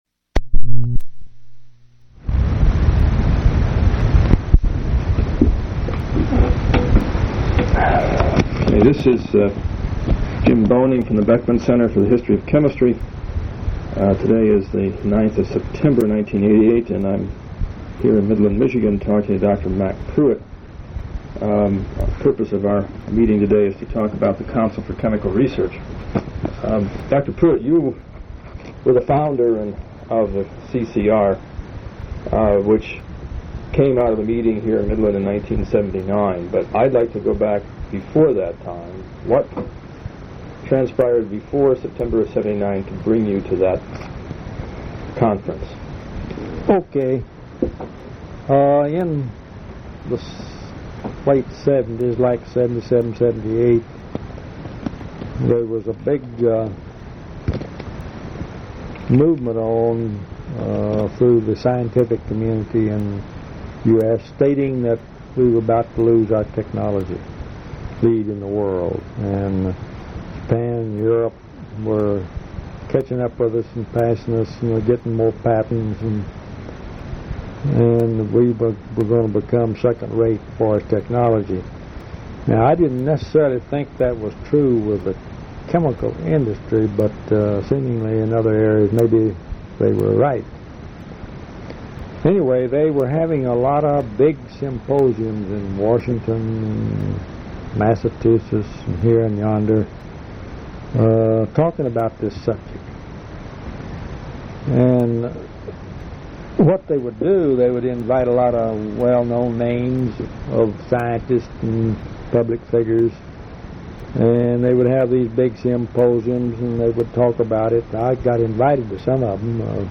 Genre Oral histories